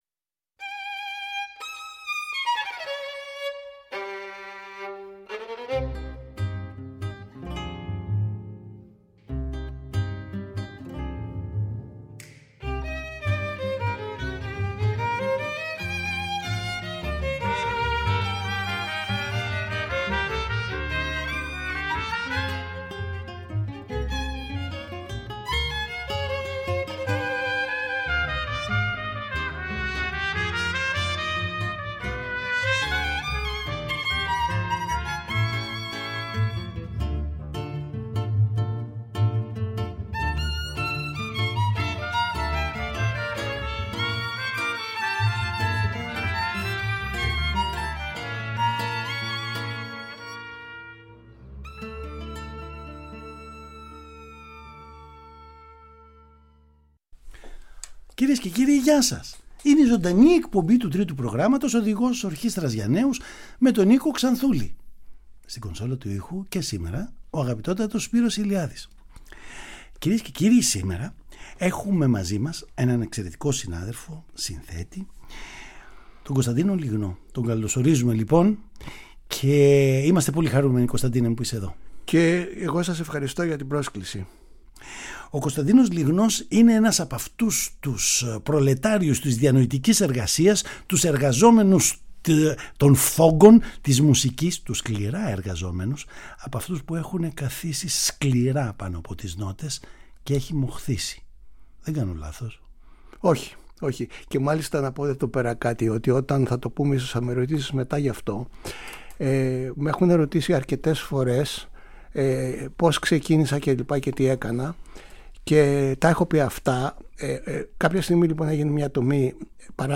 Ετοιμαστείτε για όμορφες συζητήσεις…..